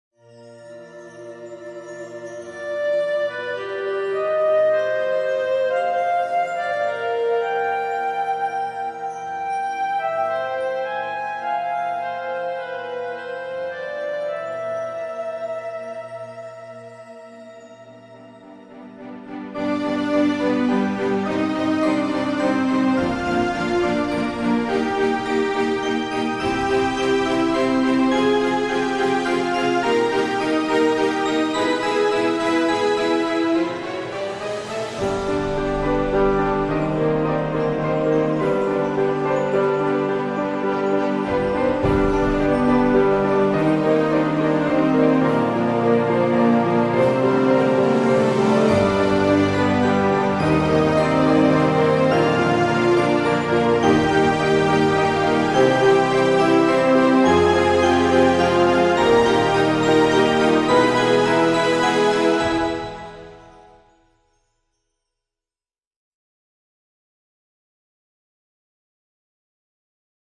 Orchestral remake of part of the background music
The entire track is made in Fl Studio 10 with ReFX Nexus.